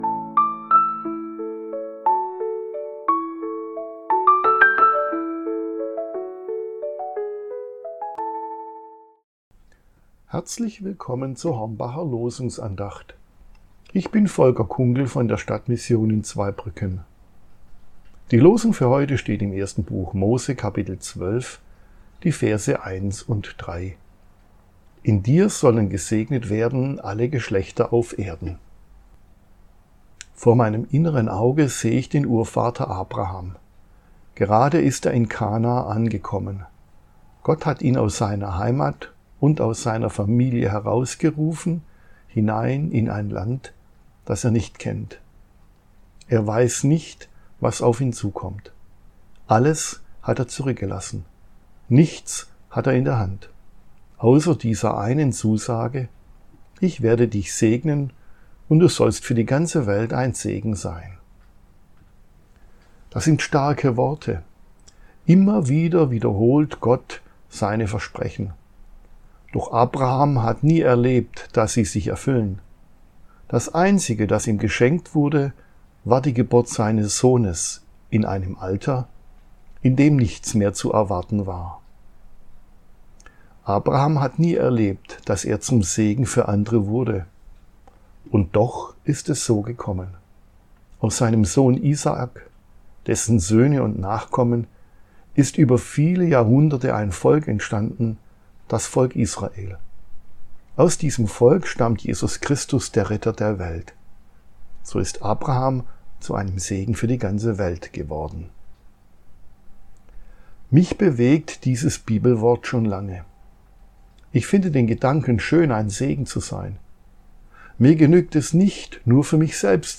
Losungsandacht für Donnerstag, 09.10.2025